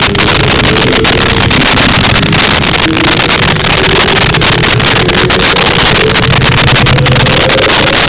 Sfx Pod Varipitch D Loop
sfx_pod_varipitch_d_loop.wav